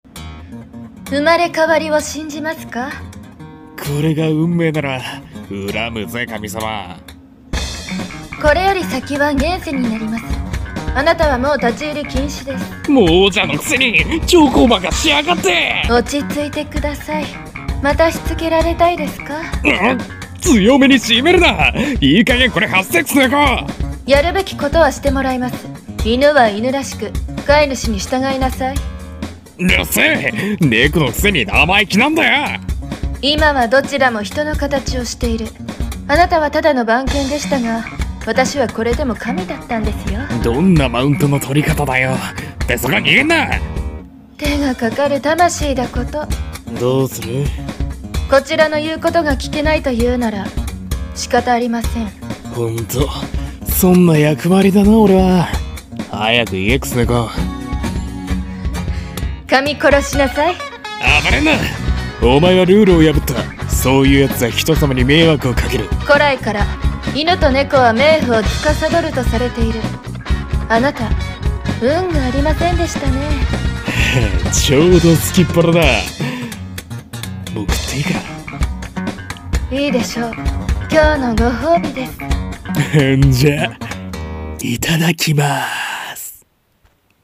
二人声劇